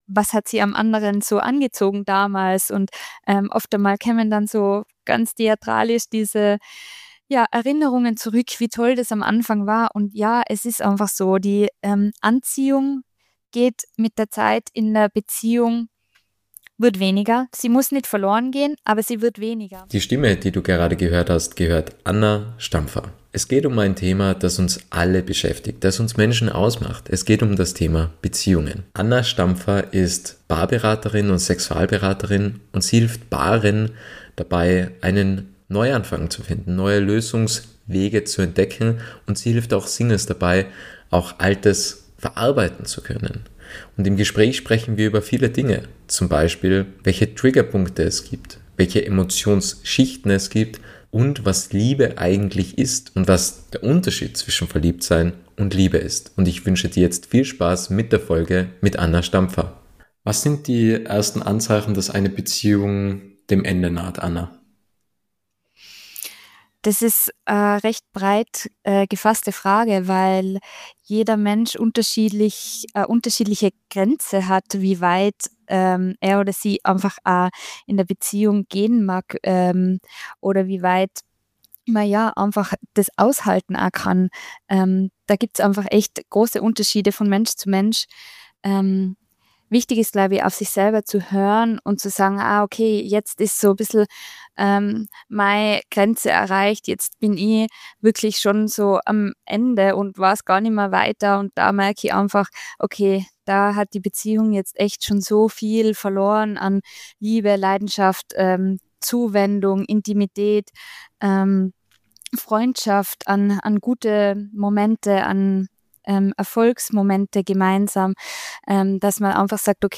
Podcast-Interview